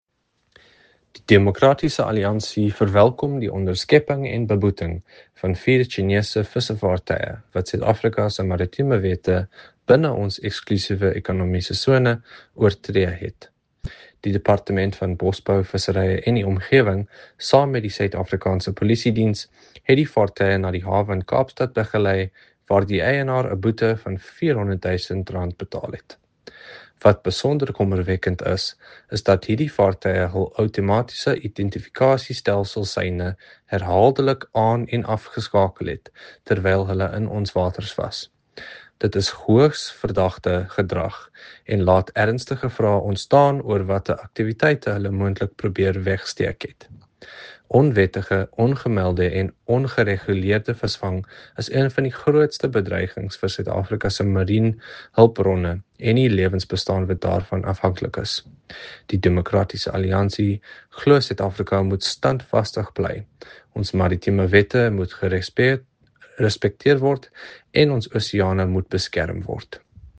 Afrikaans soundbites by Andrew de Blocq MP.
Andrew-de-Blocq_Afrikaans_-Chinese-fishing-vessels.mp3